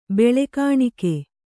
♪ beḷe kāṇike